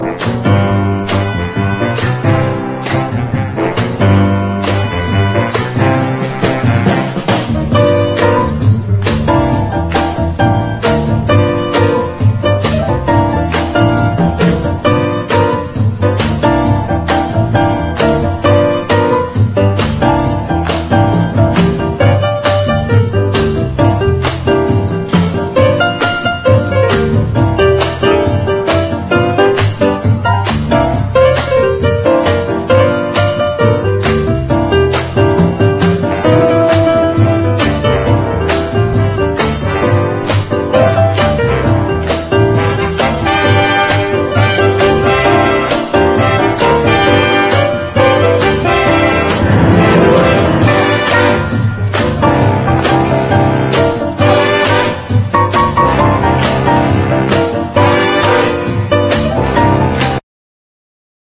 JAZZ / MAIN STREAM / BE BOP
レジェンドが集ったセッション録音！